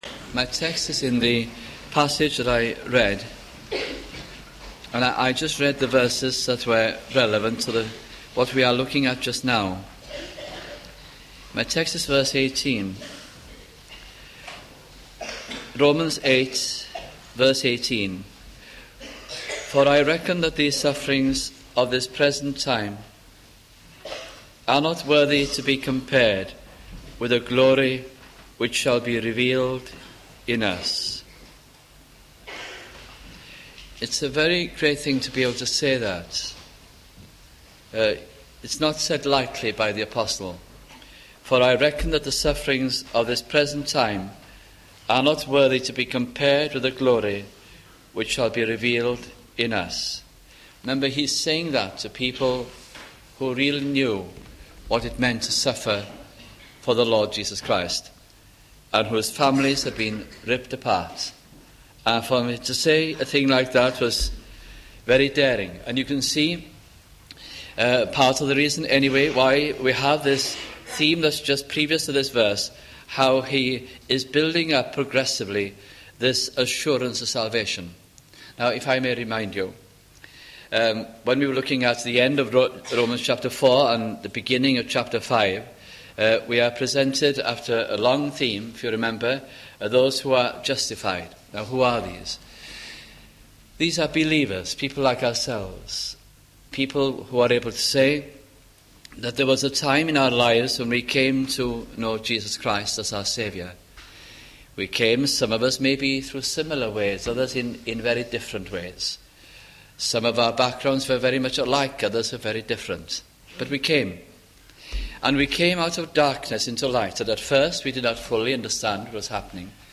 » Romans Series 1987 - 1988 » sunday morning messages